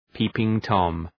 Προφορά
{‘pi:pıŋ,tɒm}